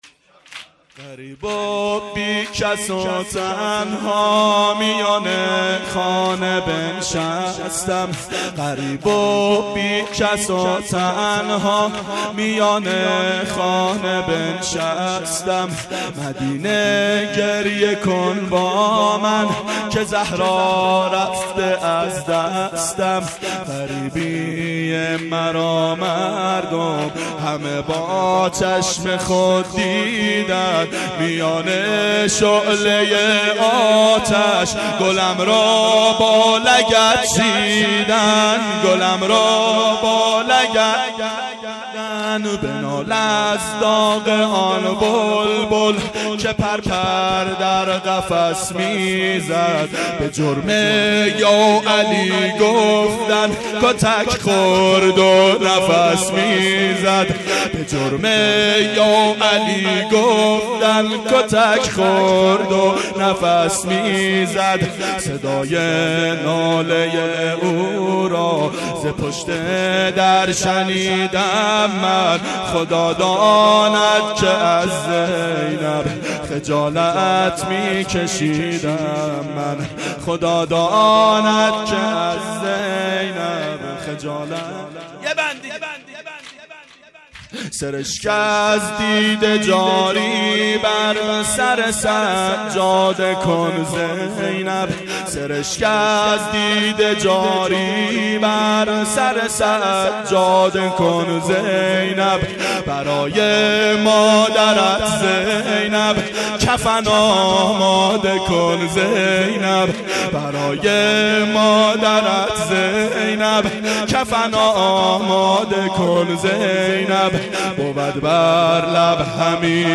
• ظهر شهادت حضرت زهرا سلام الله علیها1392 هیئت شیفتگان حضرت رقیه س